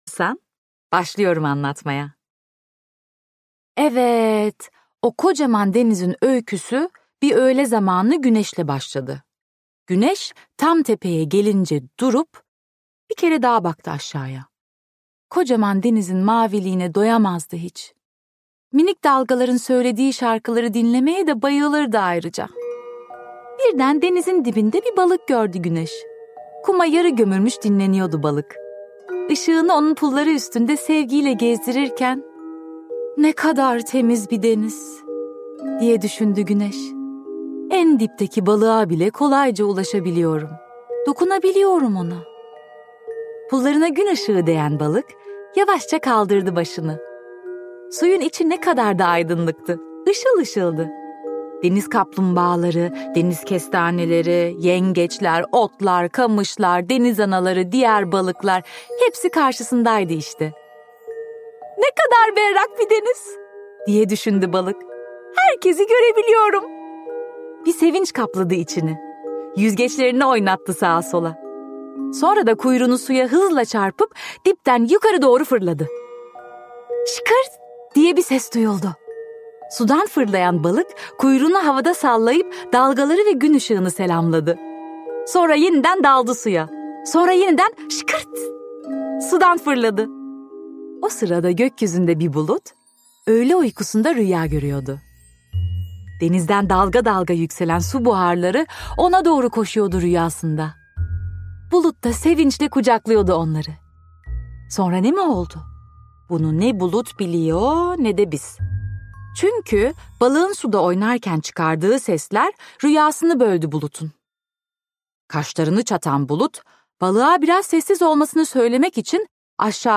Seslendiren
SELMA ERGEÇ